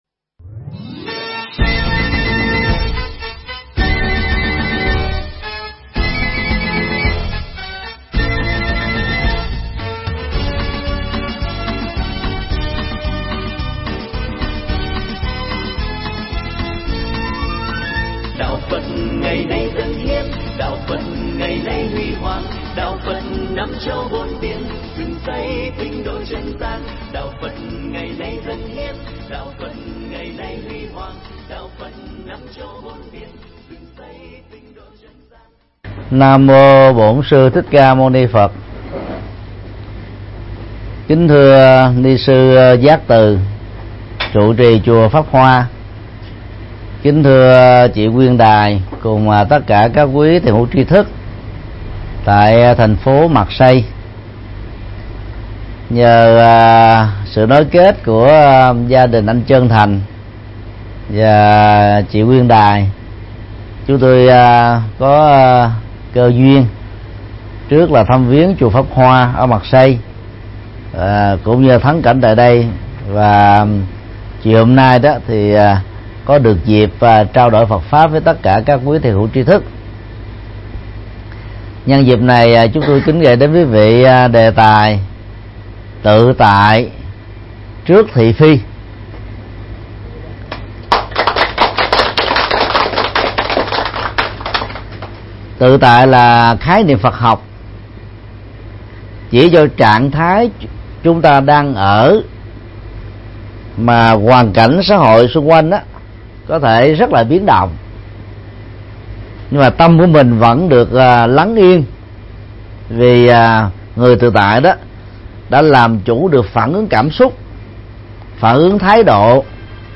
Mp3 Thuyết Pháp Tự tại trước thị phi - Thầy Thích Nhật Từ Giảng tại nhà hàng Perle D´Asie, TP. Marseille, Pháp, ngày 16 tháng 7 năm 2015